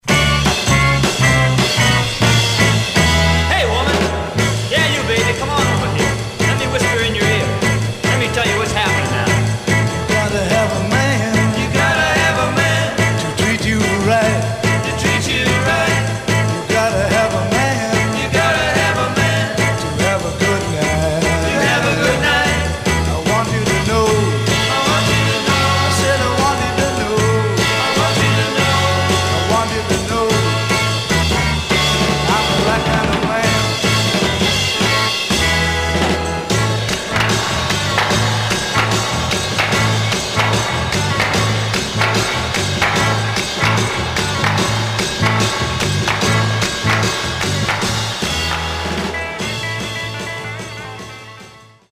Some surface noise/wear
Mono
Garage, 60's Punk